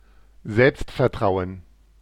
Ääntäminen
IPA : /ˈkɒn.fɪ.dəns/